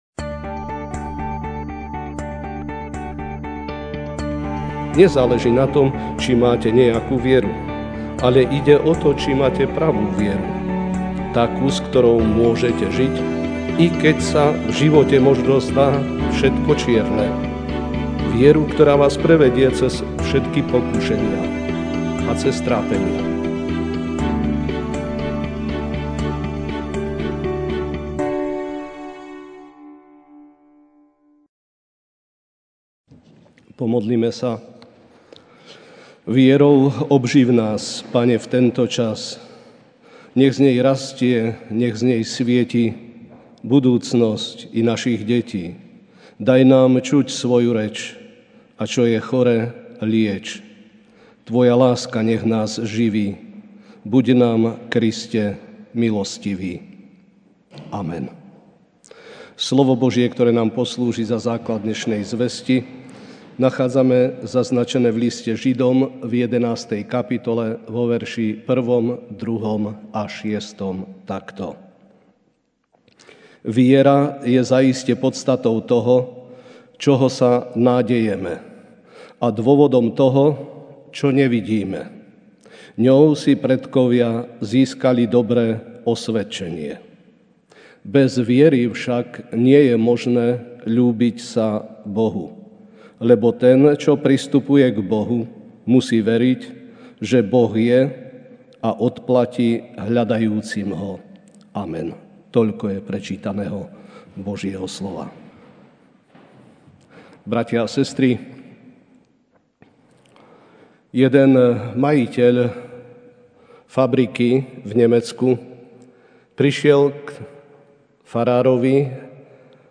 máj 19, 2019 Pravá viera MP3 SUBSCRIBE on iTunes(Podcast) Notes Sermons in this Series Večerná kázeň: Pravá viera (Žd 11, 1-2.6) A viera je podstatou toho, na čo sa človek nadeje, presvedčením o veciach, ktoré sa nevidia.